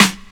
07_Snare_05_SP.wav